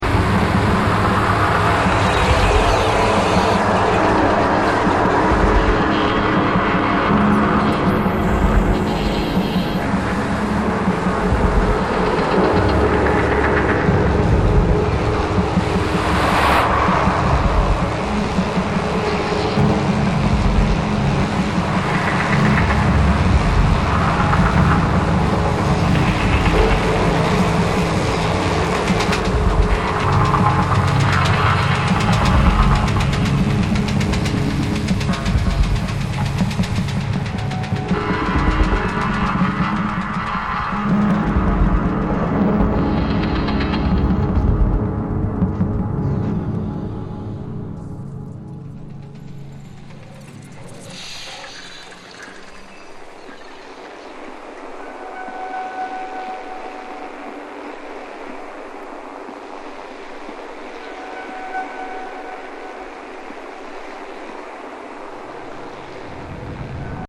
electro-acoustic music